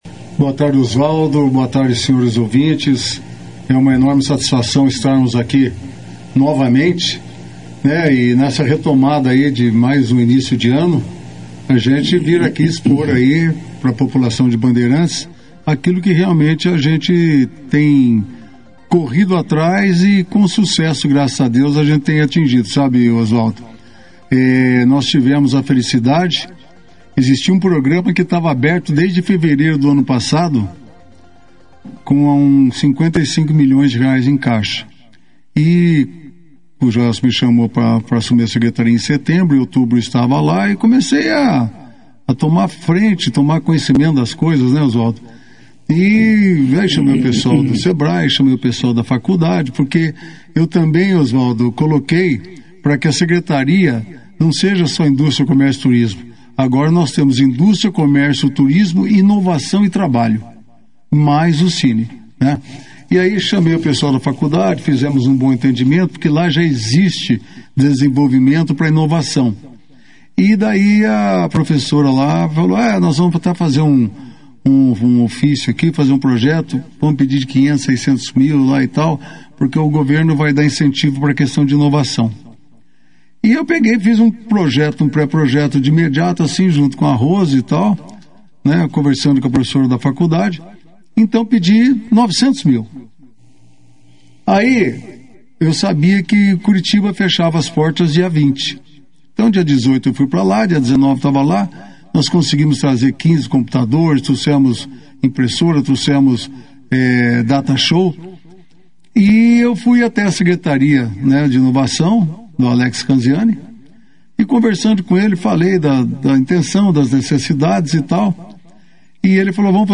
O secretário de Desenvolvimento Econômico de Bandeirantes e coordenador do Sine, Guilherme Meneghel, participou nesta quinta-feira (15) da 2ª edição do Jornal Operação Cidade, onde apresentou conquistas da secretaria e as perspectivas para o ano.
Durante a entrevista, Guilherme destacou a geração de empregos no município, mas fez um alerta sobre a falta de comprometimento de alguns trabalhadores, especialmente em funções de linha de produção, o que tem resultado em dispensas.